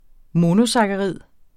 Udtale [ ˈmonosɑgaˌʁiðˀ ]